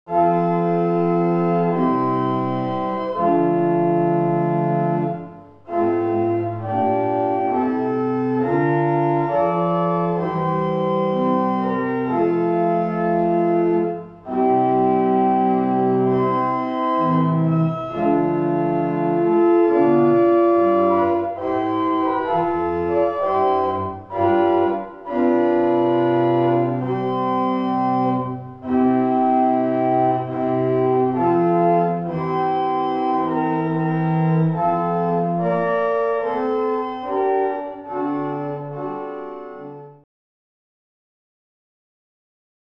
Aux claviers de l'orgue de la Synagogue de Nazareth (ou au piano)